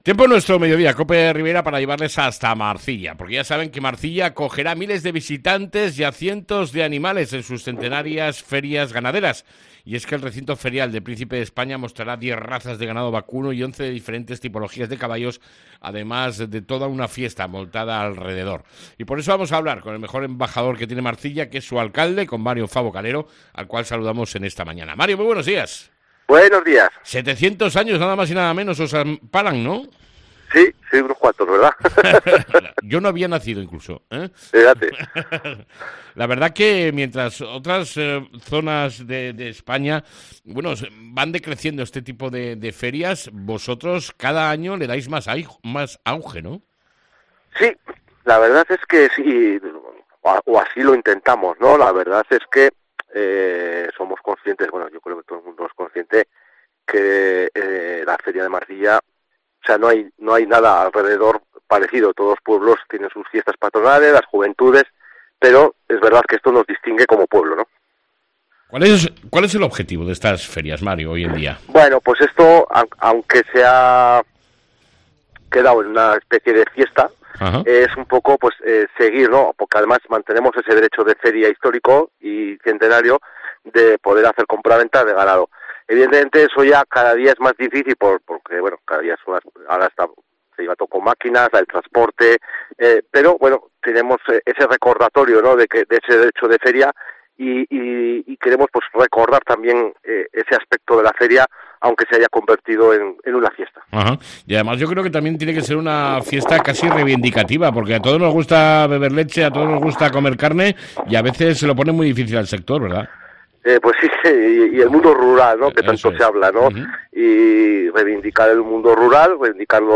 ENTREVISTA CON EL ALCALDE DE MARCILLA SOBRE LAS FERIAS 2022